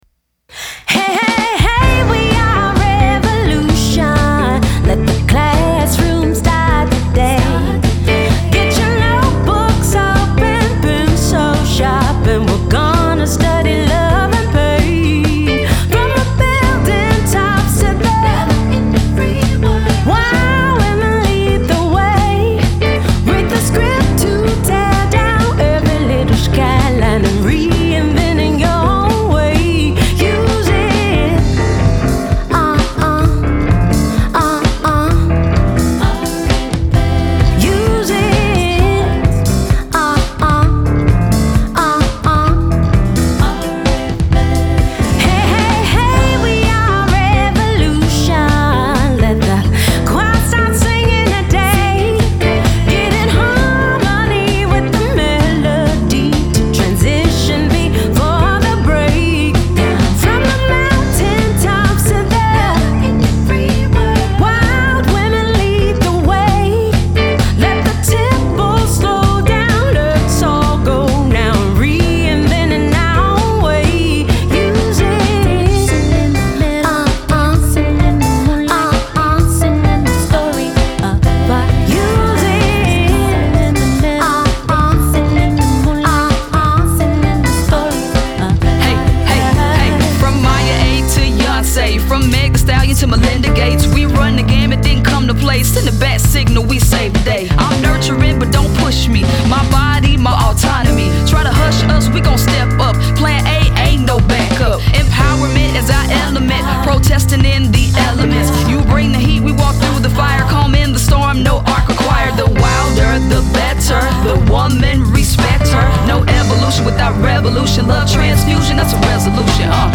Genre: Folk, Soul, Rock & Roll